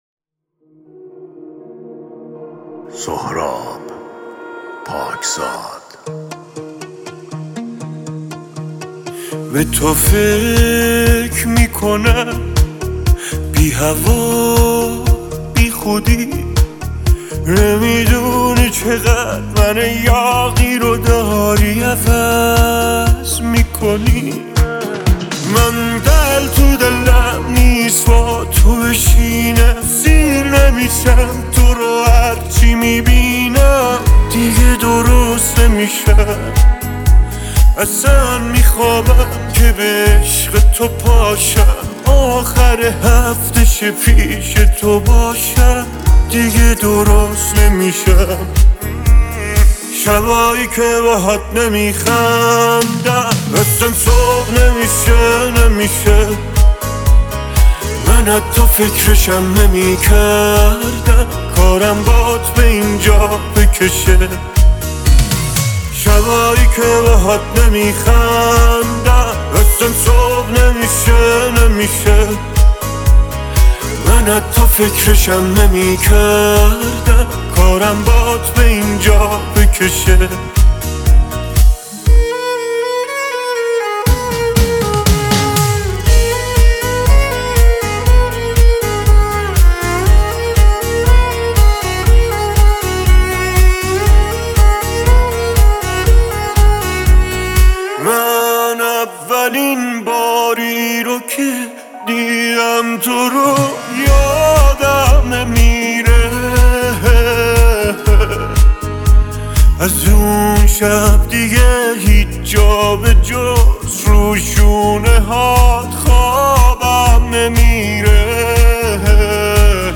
او بیشتر به خاطر آهنگ‌های پاپ و عاشقانه خود شناخته می‌شود.